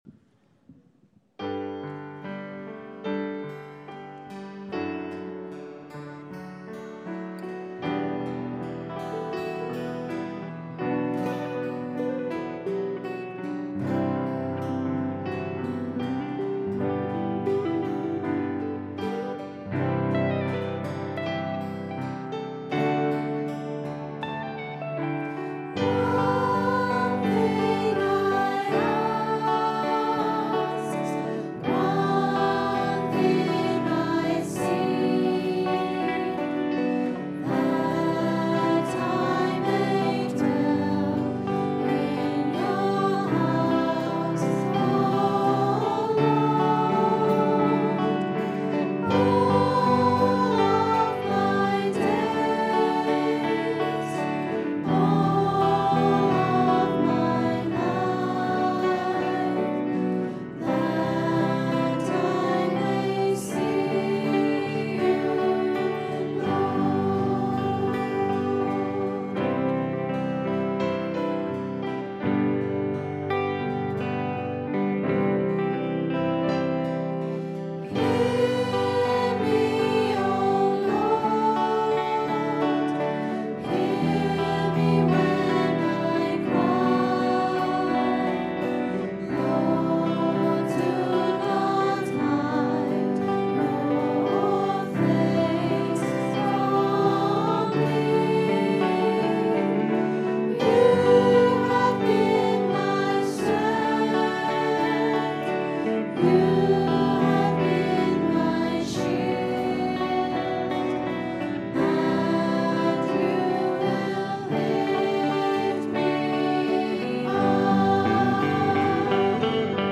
Recorded on a Zoom H4 digital stereo recorder at 10am Mass Sunday 8th September 2013.